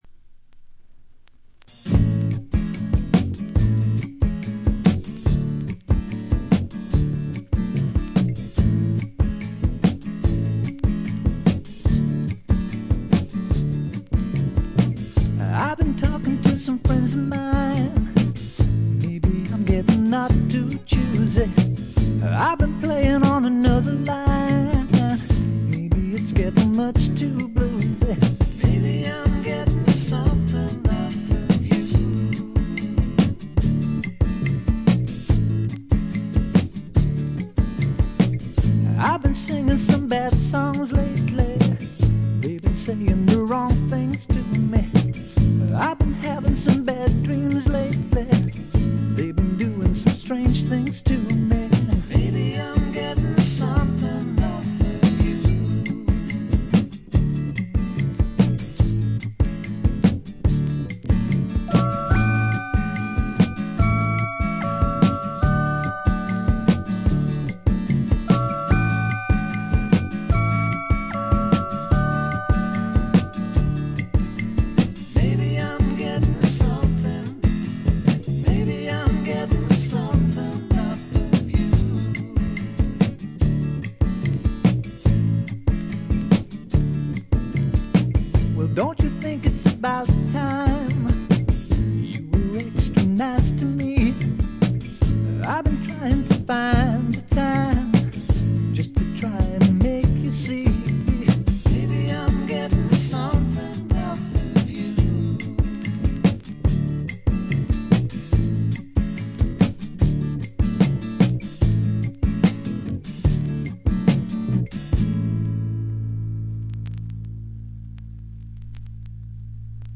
B面は静音部でチリプチ。
味わい深い美メロの良曲が連続する快作。
試聴曲は現品からの取り込み音源です。